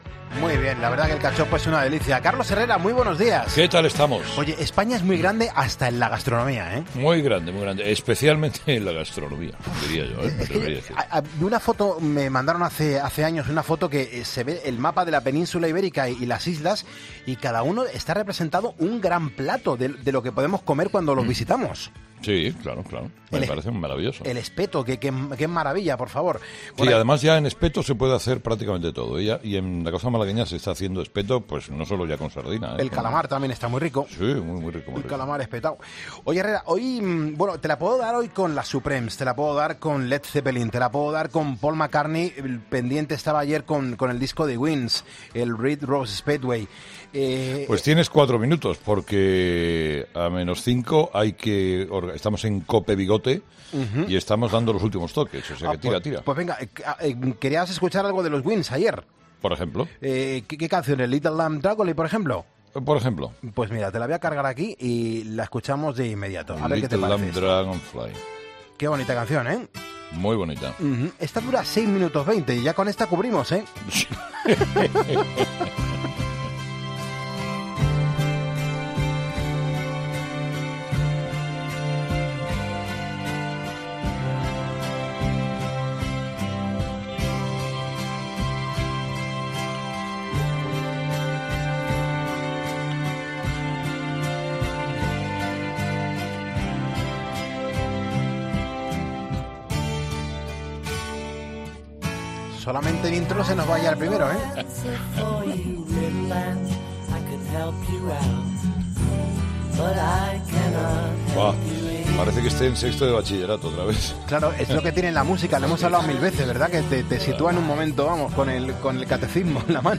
escuchan brevemente un par de canciones de Paul McCartney & Wings y Led Zeppelin.